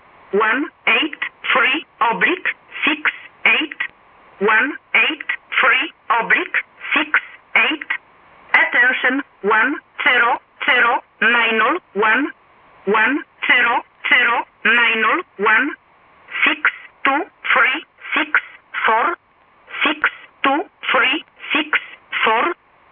Added samples of a 100 Bd / 625 Hz FSK waveform used by Polish intelligence for one-way broadcasts via HF.